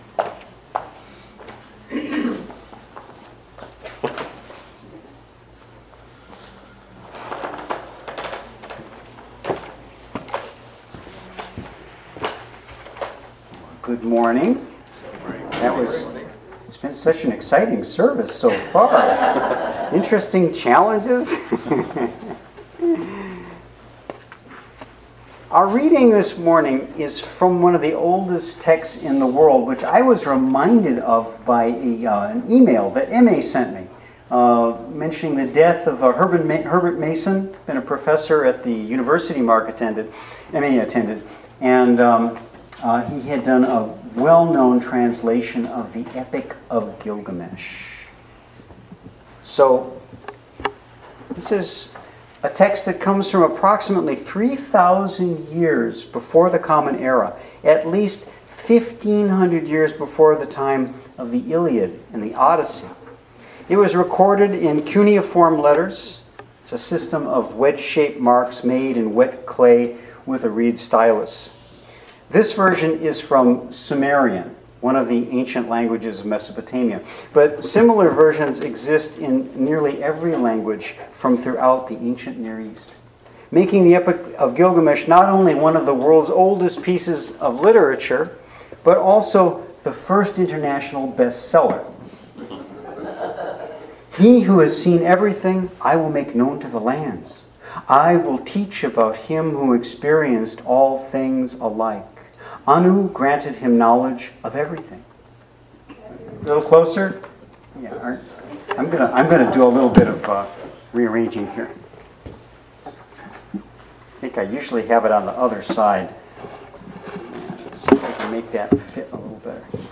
Sermon Archive | Wy'east Unitarian Universalist Congregation